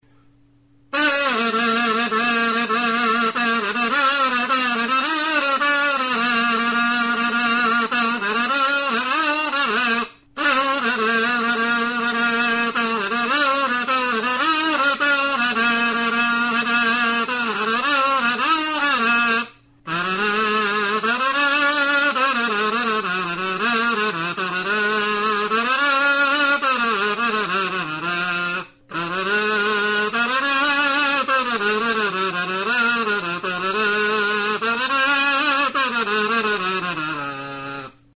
Membranophones -> Mirliton
Recorded with this music instrument.